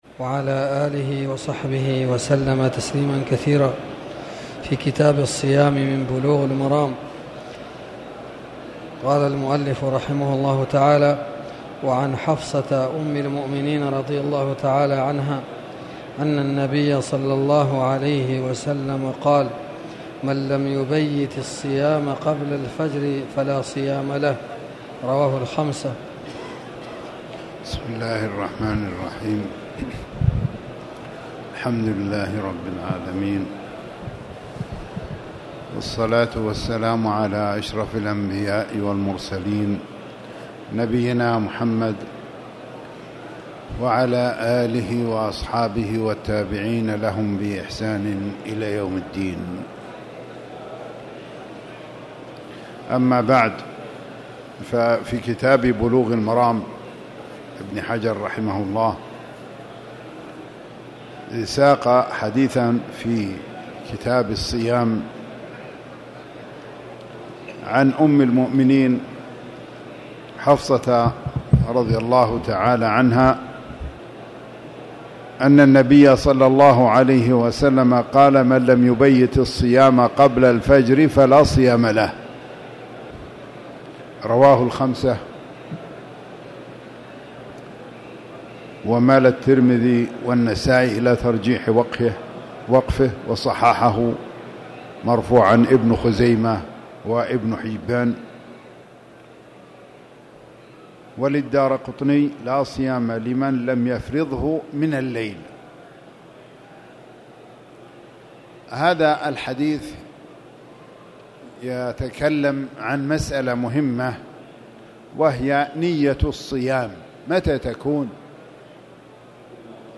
تاريخ النشر ٦ رمضان ١٤٣٧ هـ المكان: المسجد الحرام الشيخ